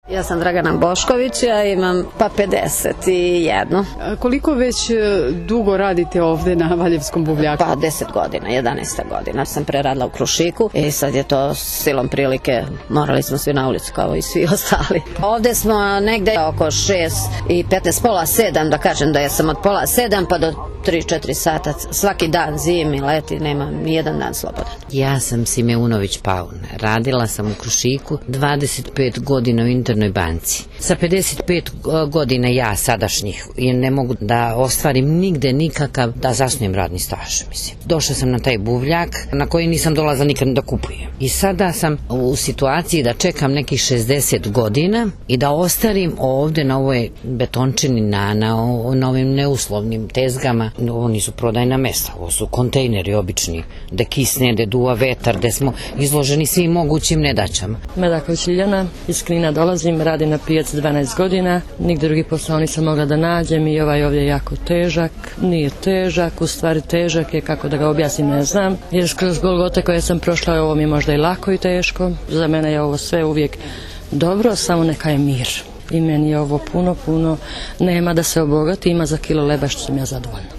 Anketa na Buvljoj pijaci u Valjevu